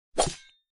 add attack sound
swing.wav